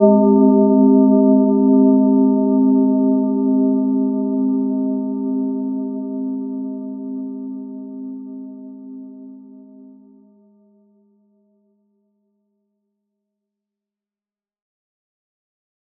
Gentle-Metallic-2-C4-mf.wav